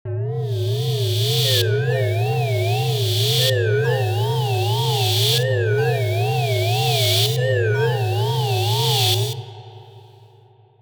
Some more random generated drones, is going to pair very well with Lyra 8